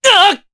Kasel-Vox_Damage_jp_03.wav